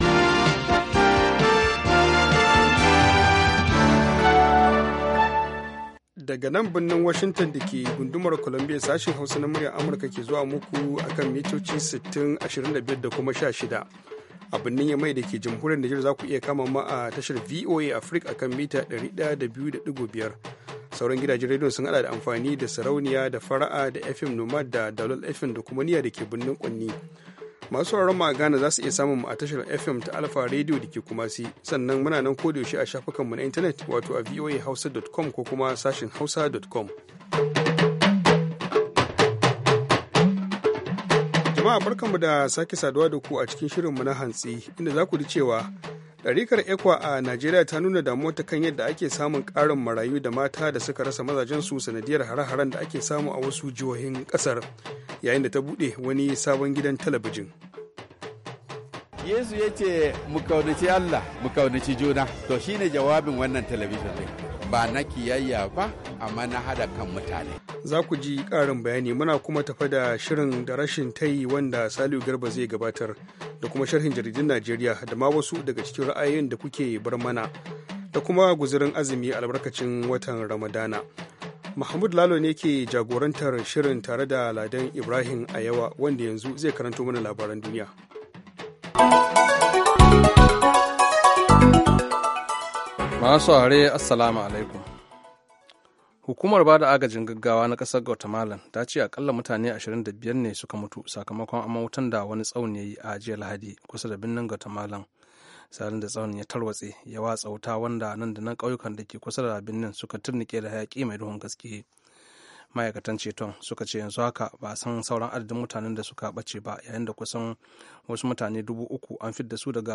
Mu kan komo da karfe 8 na safe agogon Najeriya da Nijar domin sake gabatar muku da labarai da hirarraki, da sharhin jaridu kama daga Najeriya zuwa Nijar har Ghana, da kuma ra’ayoyinku.